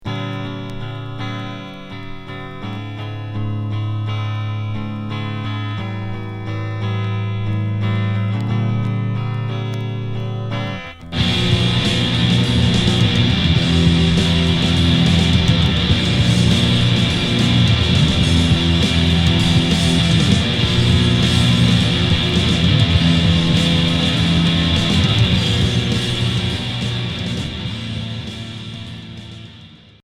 Noisy pop Troisième 45t